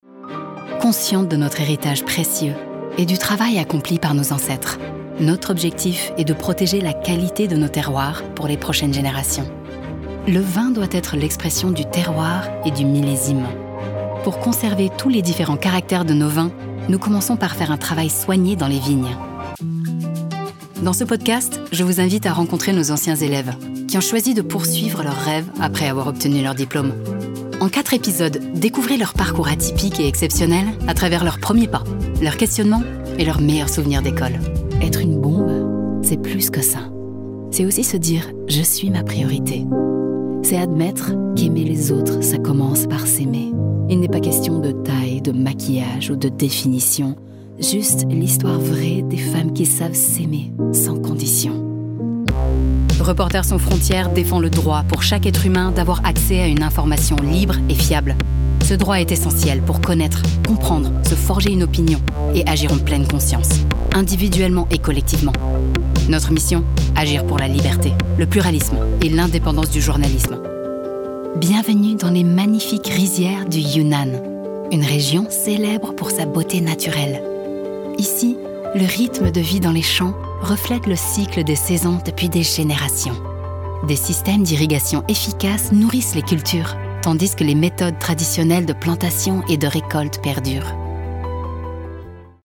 Kommerziell, Zuverlässig, Warm
Audioguide
- A voice over artist with an eloquent, neutral French.
- Extremely clear diction with perfect enunciation.
- My voice has been described as smooth, warm, friendly and captivating.